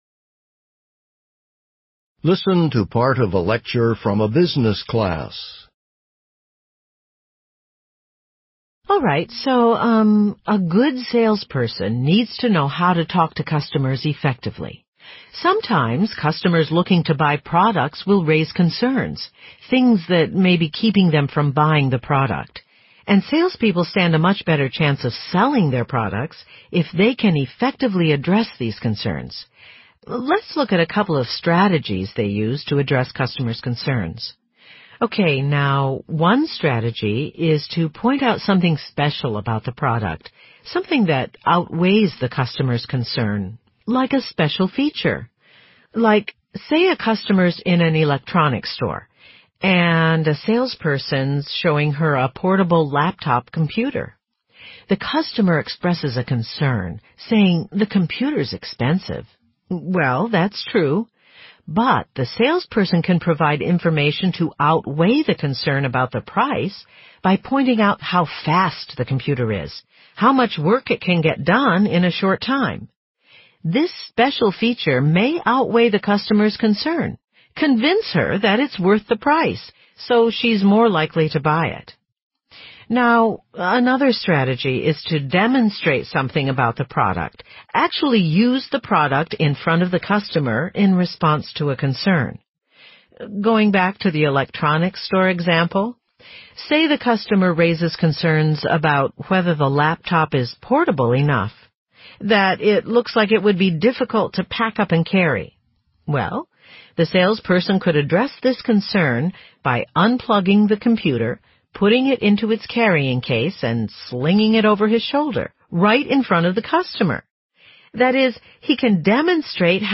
Using points and examples from the lecture, explain two strategies salespeople use to address customer concerns.